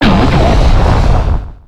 Cri de Démétéros dans Pokémon X et Y.